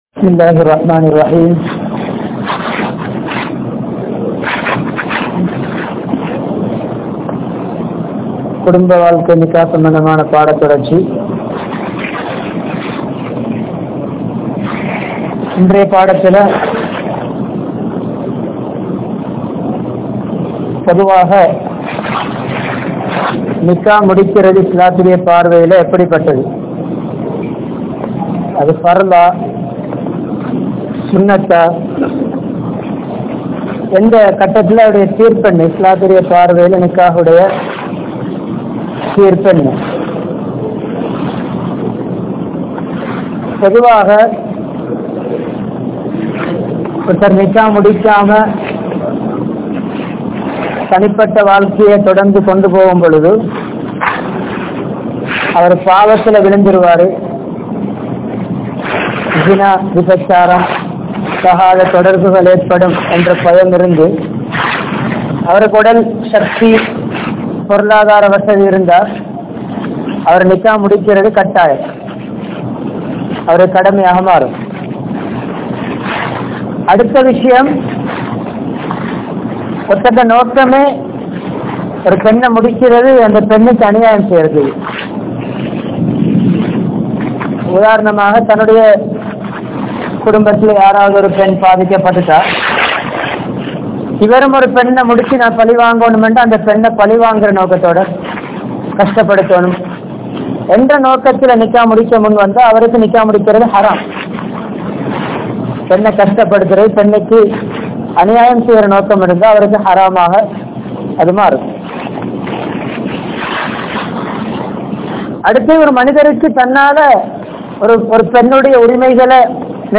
Kudumba Vaalkai (Thafseer) (குடும்ப வாழ்க்கை) | Audio Bayans | All Ceylon Muslim Youth Community | Addalaichenai
Jamiul Falah Jumua Masjidh